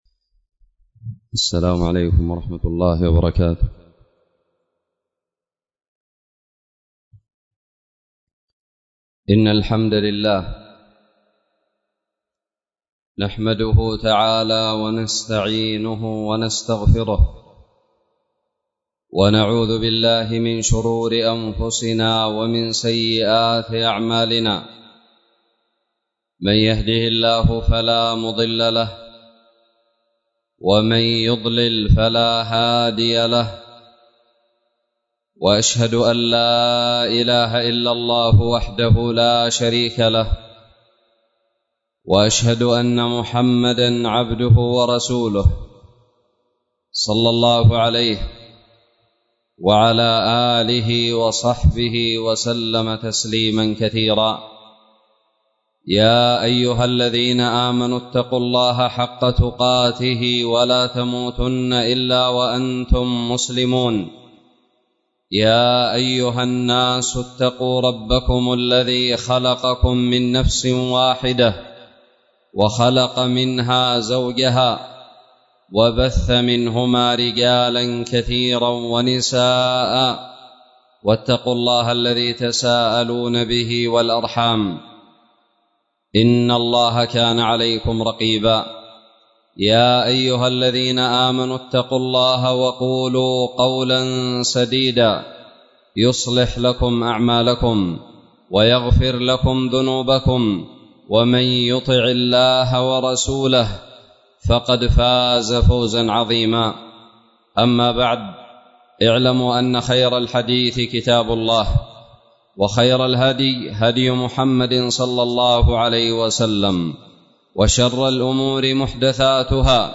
خطب الجمعة
ألقيت بدار الحديث السلفية للعلوم الشرعية بالضالع في 11 رمضان 1442هــ